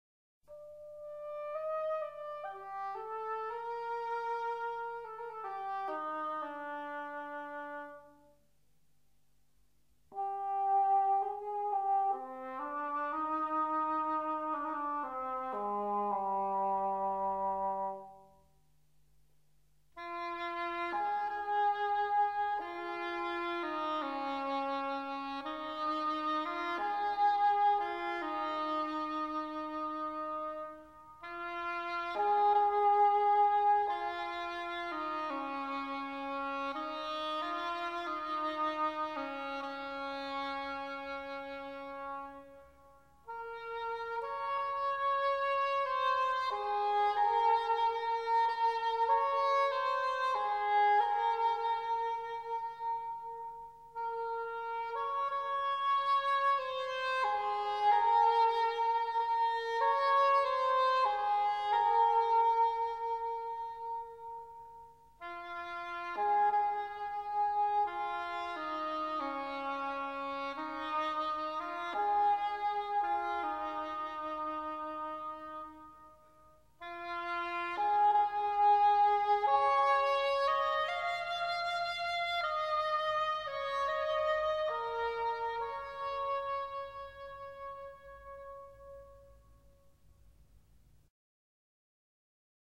11 Corno Inglés.wma